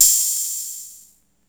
606ohat.wav